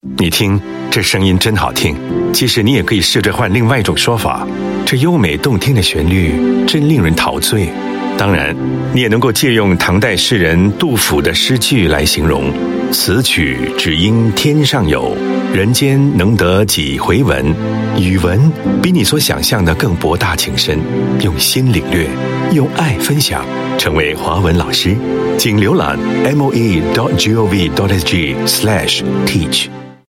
Voice Samples: Soft Read (Mandarin)
male